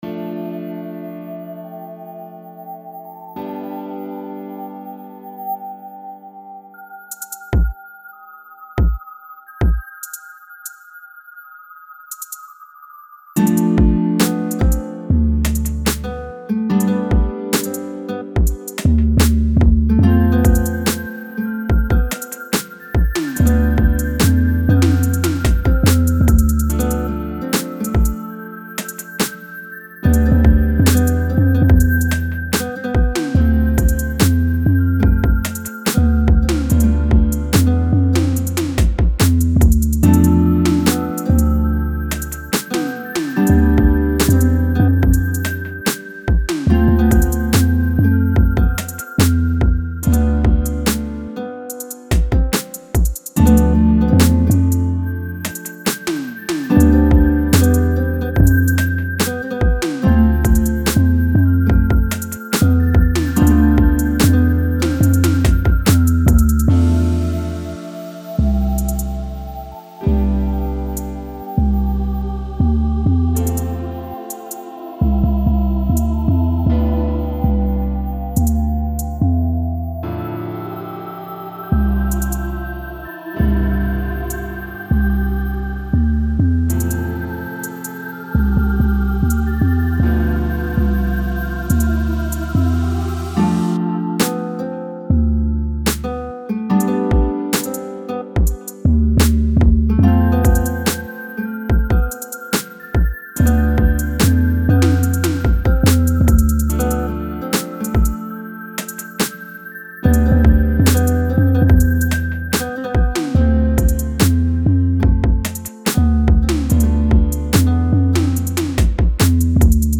guitar_begin22-copy.mp3